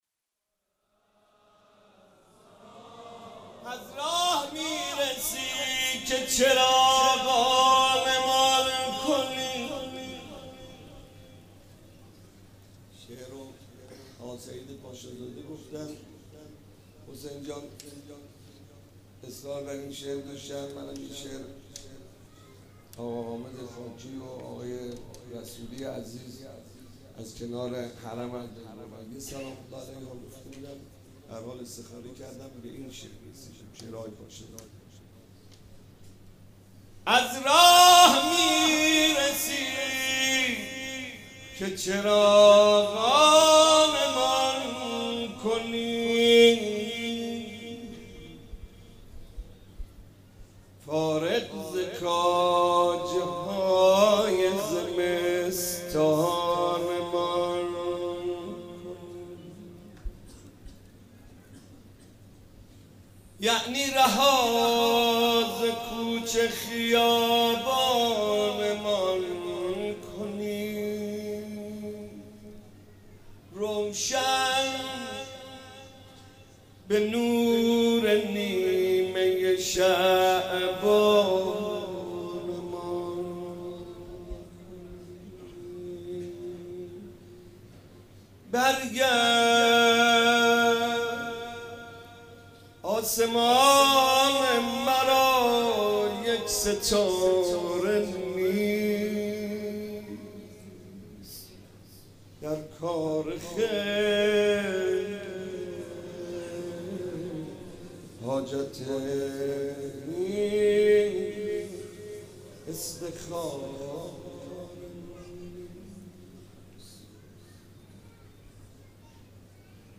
مناسبت : ولادت حضرت مهدی عج‌الله تعالی‌فرج‌الشریف
قالب : مدح